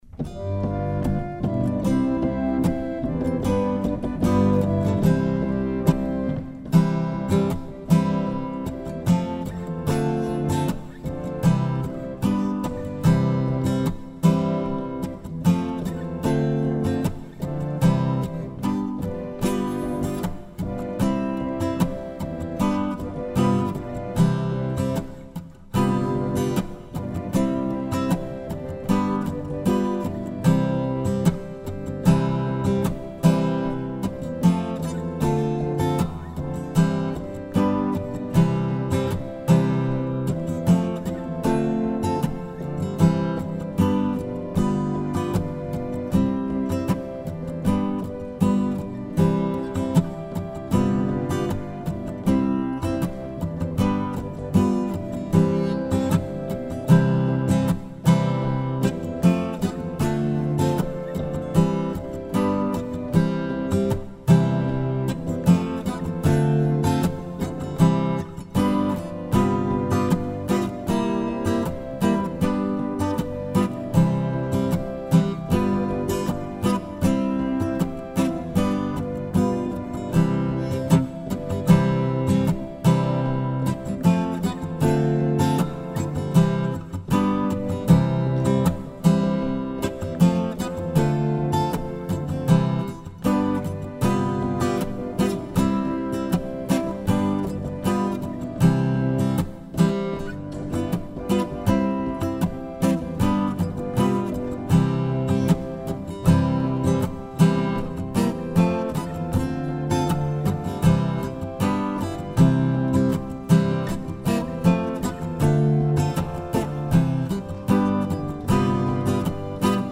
Tämä balladi perustuu tositapahtumiin.
Tämä laulu on suomalaisen miehisen uhon symboli ja se kuuluu esittää asianmukaisen voimakkaasti.